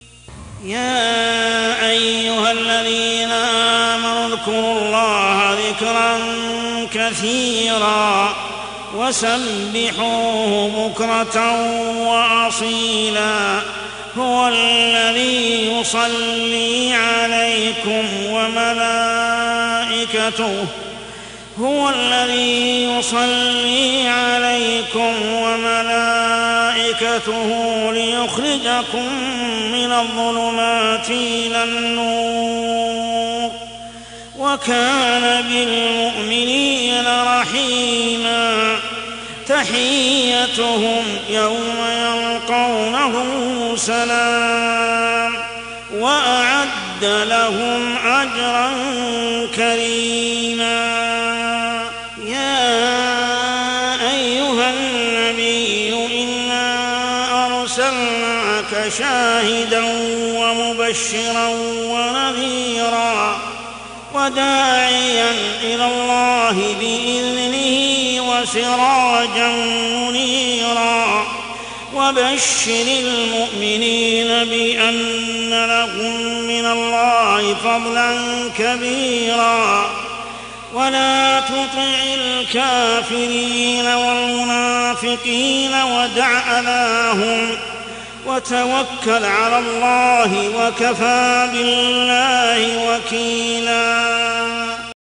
عشائيات شهر رمضان 1424هـ سورة الأحزاب 41-48 | Isha prayer Surah Al-Ahzab > 1424 🕋 > الفروض - تلاوات الحرمين